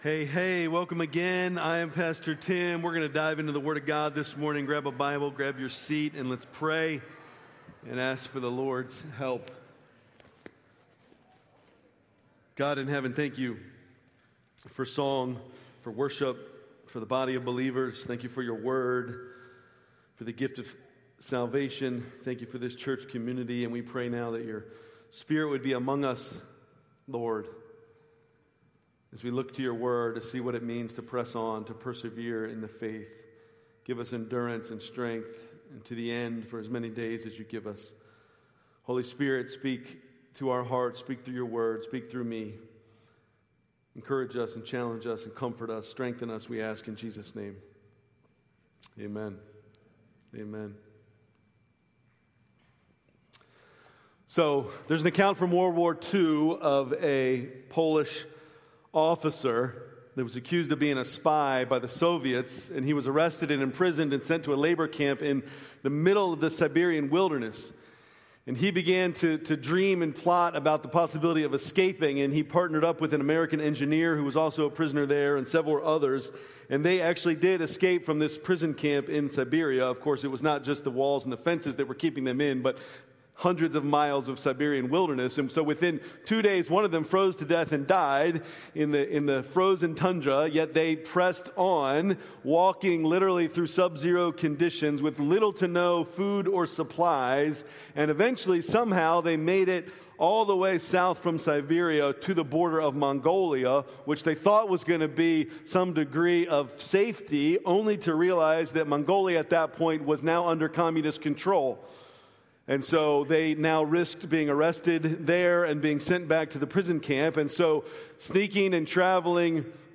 Aug 17, 2025 Worship Service Order of Service: